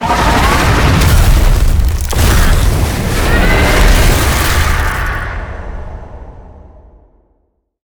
Sfx_creature_iceworm_attack_fling_01.ogg